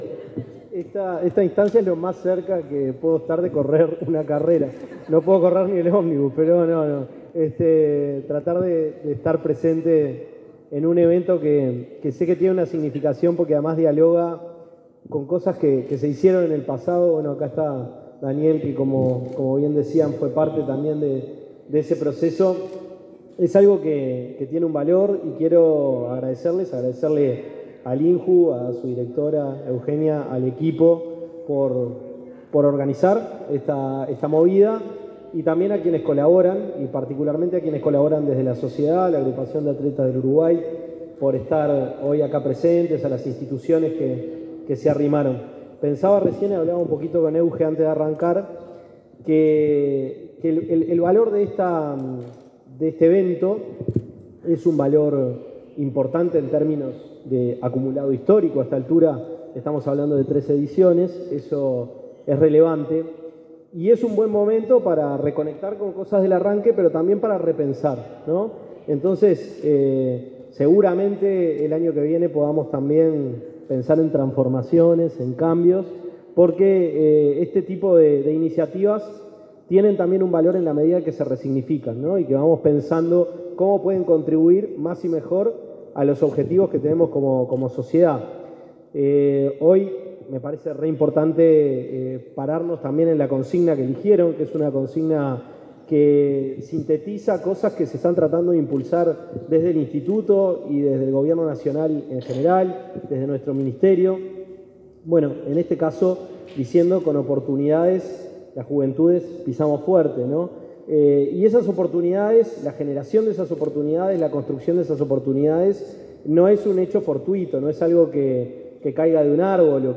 Palabras del ministro de Desarrollo Social, Gonzalo Civila
En ocasión del lanzamiento de la carrera INJU 5K, se expresó el ministro Gonzalo Civila.